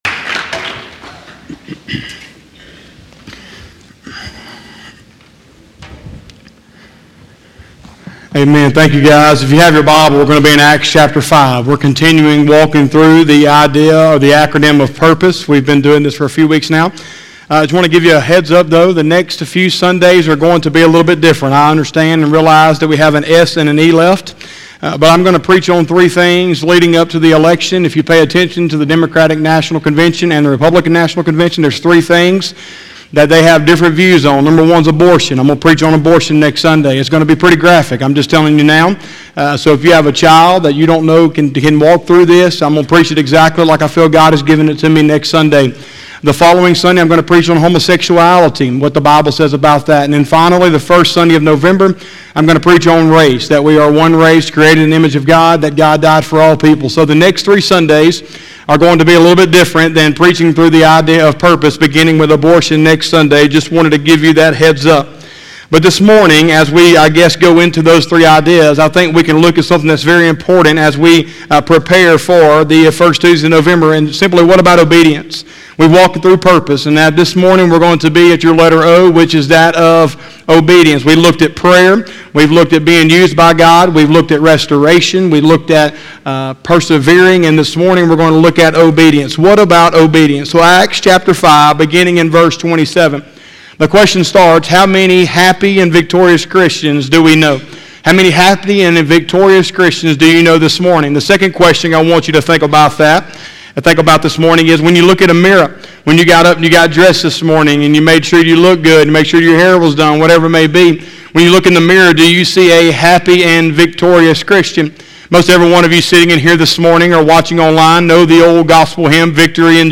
10/11/2020 – Sunday Morning Service